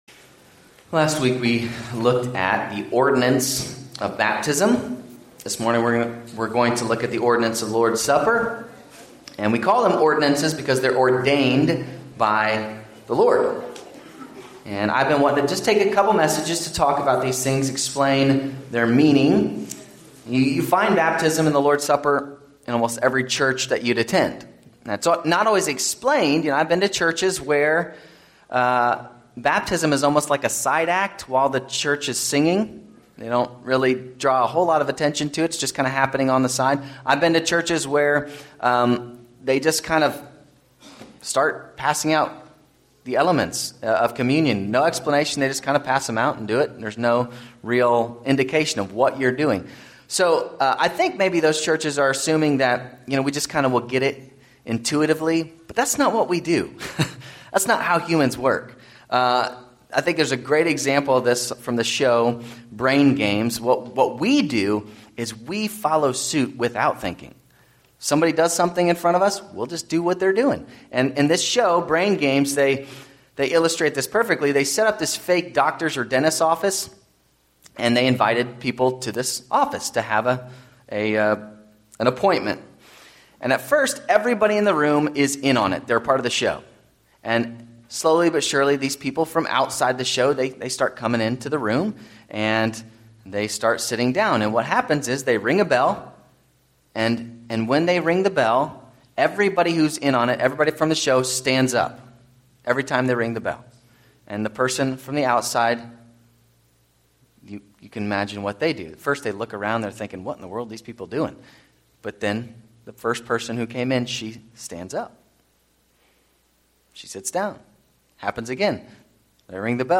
Sermons
Service Type: Sunday 10:30am